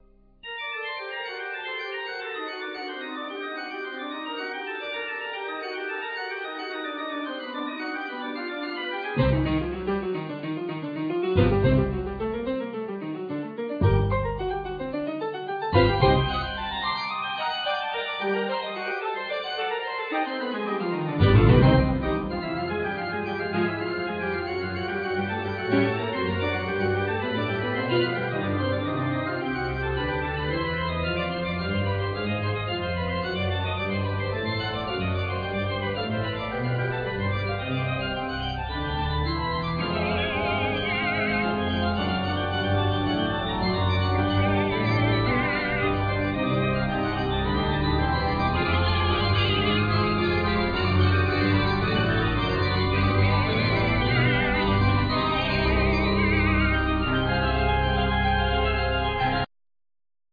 Trombone
Basson
Trampet,Trampet Piccolo
Zarb,Viele,Timbales
Harp
Soprano
Baryton
Chorus